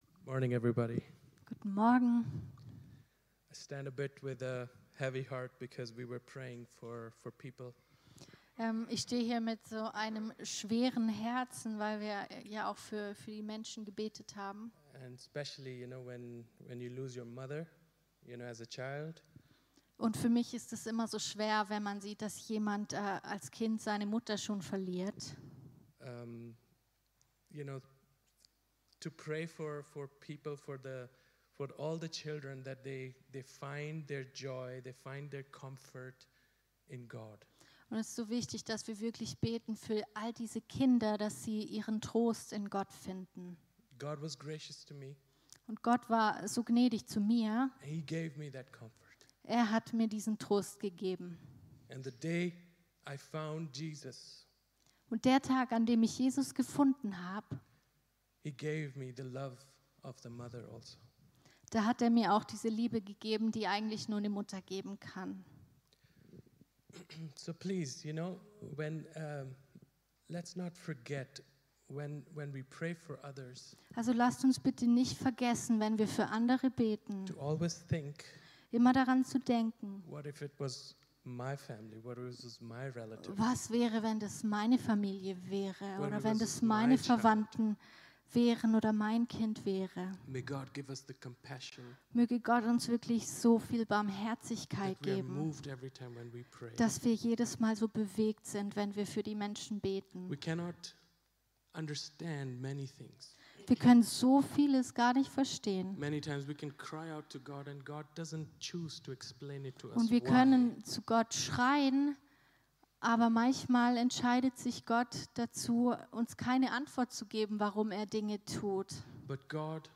Predigt
im Christlichen Zentrum Villingen-Schwenningen. - Sprache: Englisch mit deutscher Übersetzung